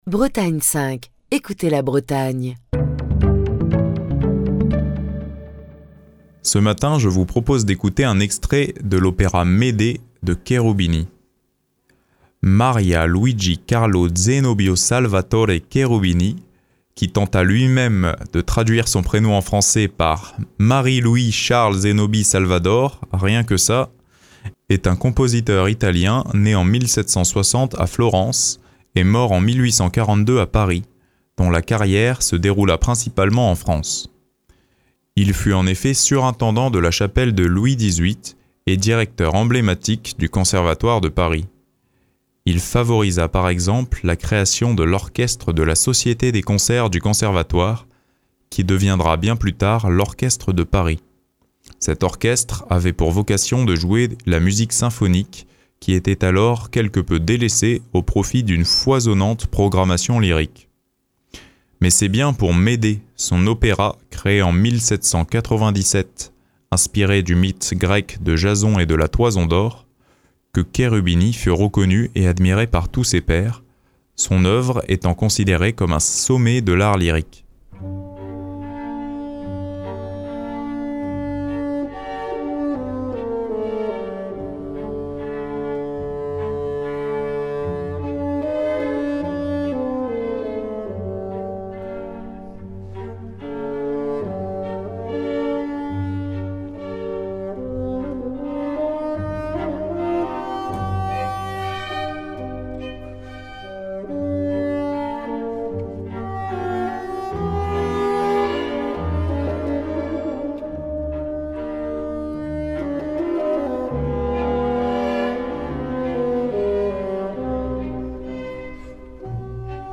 basson
qui interprète le répertoire sur instruments d'époque.